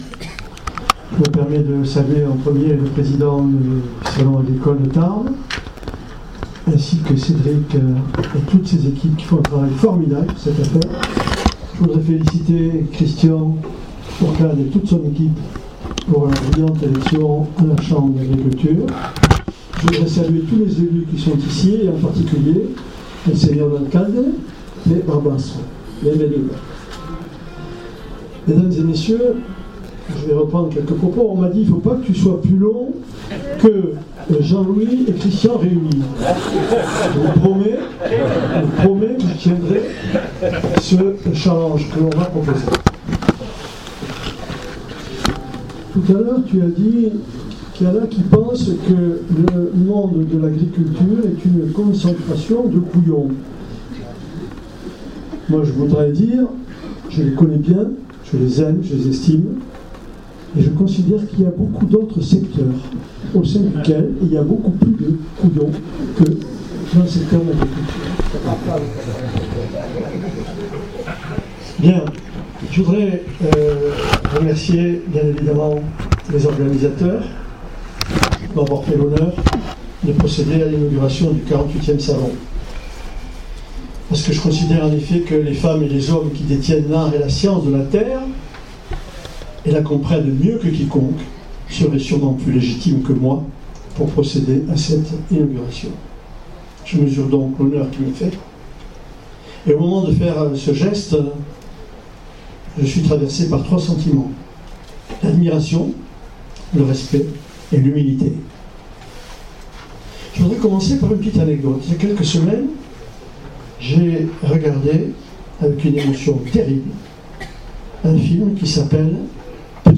Lors de l’inauguration du Salon de l’Agriculture de Tarbes ce jeudi matin, Gérard Trémège, maire de Tarbes, a souligné l’importance vitale de l’agriculture pour la France. (AUDIO) Il a noté que notre pays, bien que premier producteur européen de produits agricoles, a vu son excédent de balance commerciale agricole se réduire de moitié depuis 2021.